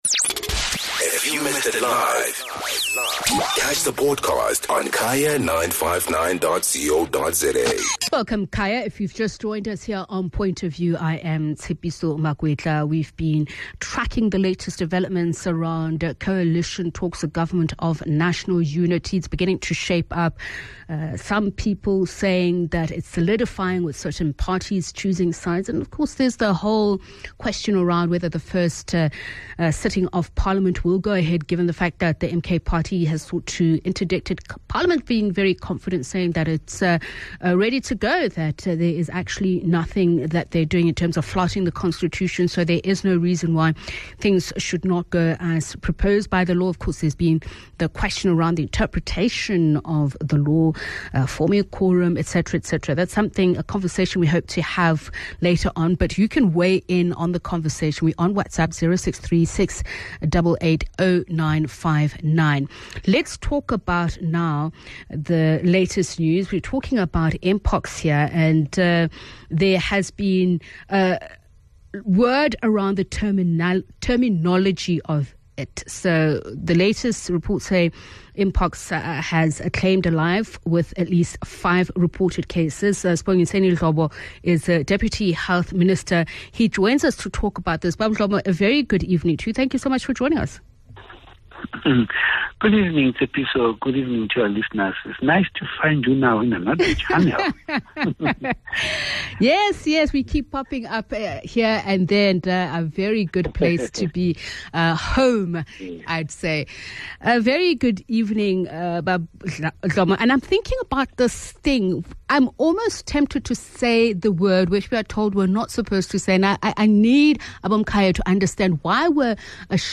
Guest: Sbongisendi Dhlomo - Deputy Health Minister